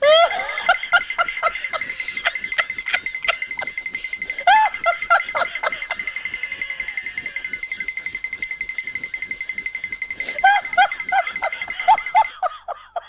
tickle2.wav